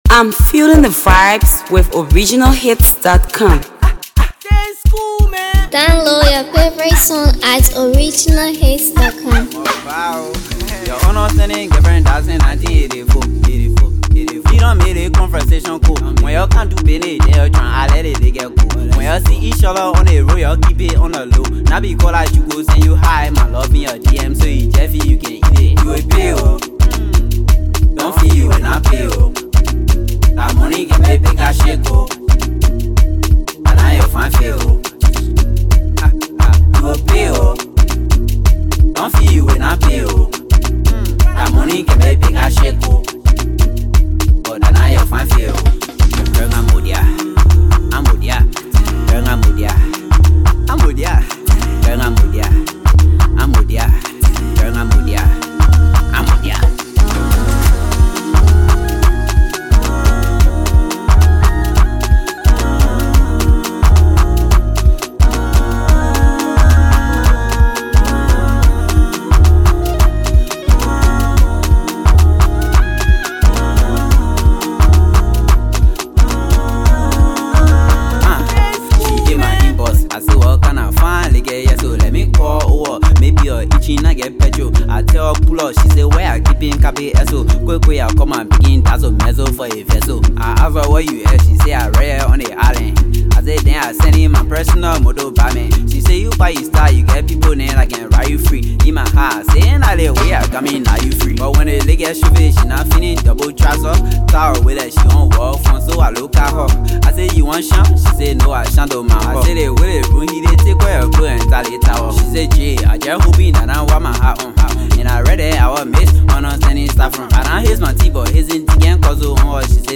Afro PopHipcoMusicTOP SONGSTop Songs